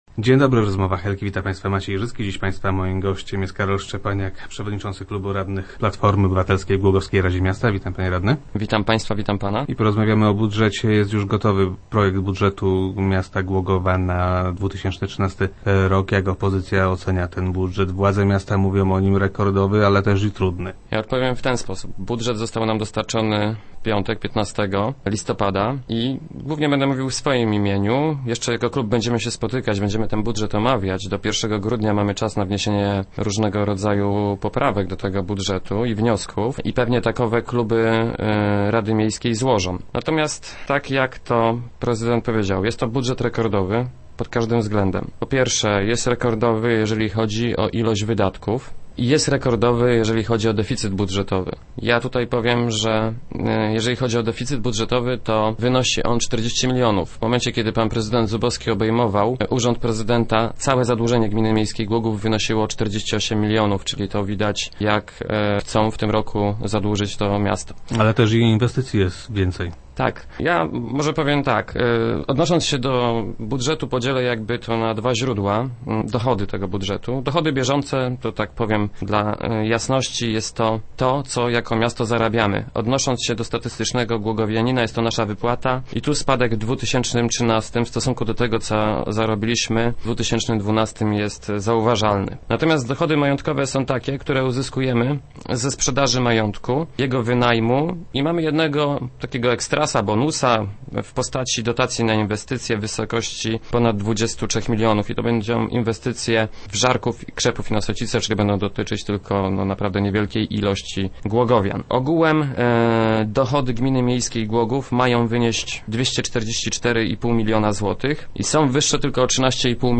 - Deficyt budżetowy wyniesie 40 milionów złotych. Kiedy prezydent Zubowski przejmował rządy, całe zadłużenie miasta wynosiło 48 milionów. Widać więc to, jak rządzący miastem chcą je zadłużyć. W przyszłym roku, jeżeli sprawdzą się prognozy dotyczące deficytu i zaciągnięte zostaną pożyczki i kredyty, to zadłużenie miasta sięgnie 110 milionów złotych - mówił radny Szczepaniak, który był gościem Rozmów Elki.